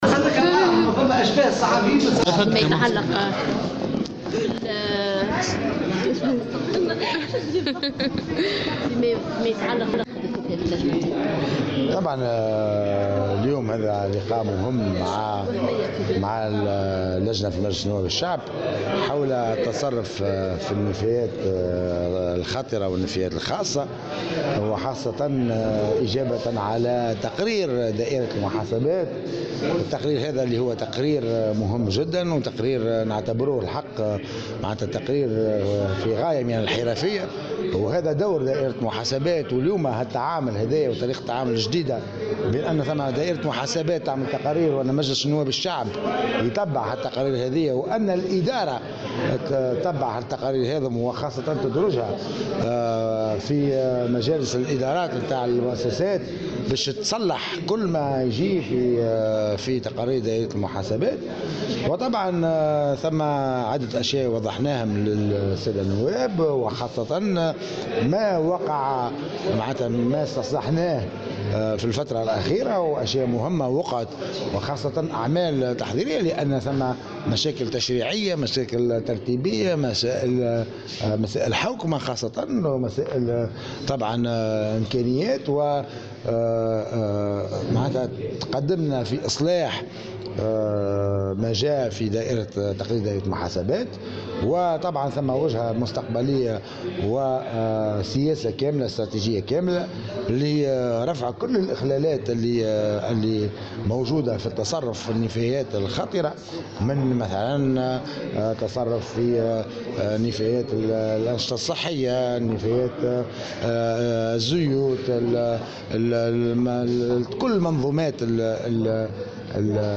استمعت اليوم لجنة الإصلاح الإداري والحوكمة الرشيدة ومكافحة الفساد ومراقبة التصرف في المال العام بمجلس نواب الشعب إلى وزير البيئة والشؤون المحلية، رياض الموخر بخصوص تقرير دائرة المحاسبات حول التصرّف في النفايات الخطيرة.